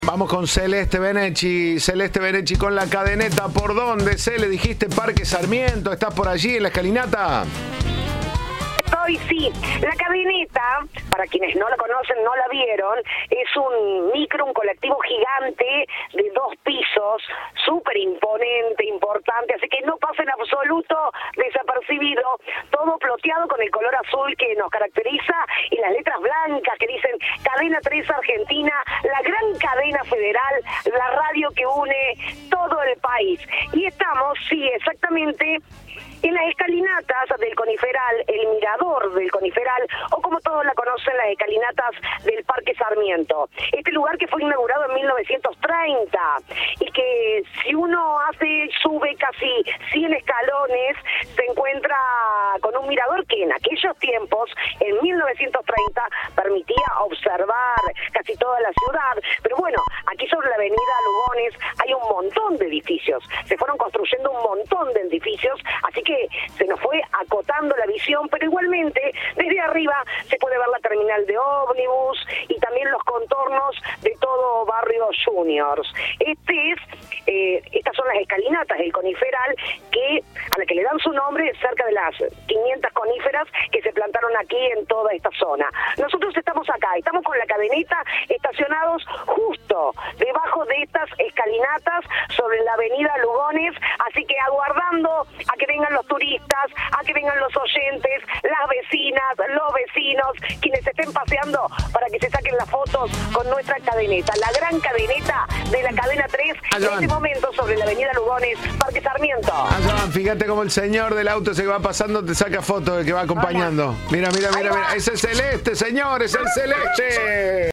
Audio. La Cadeneta desde el parque Sarmiento
Informe